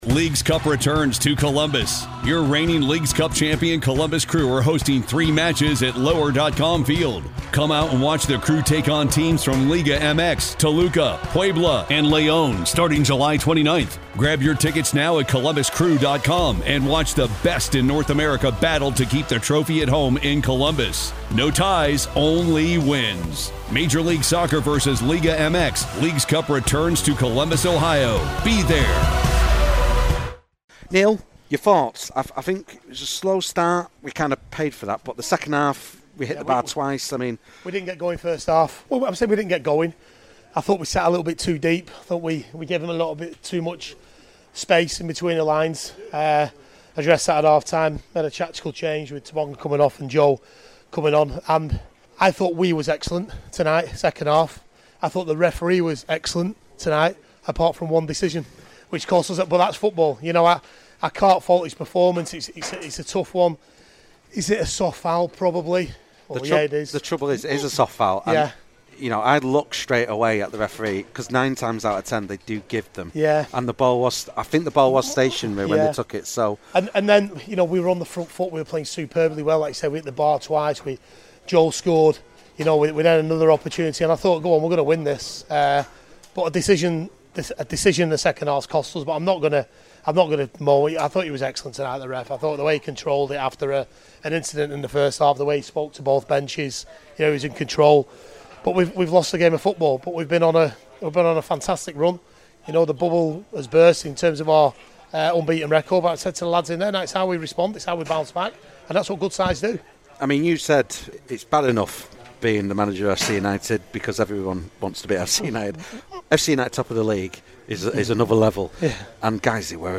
Post Match Interview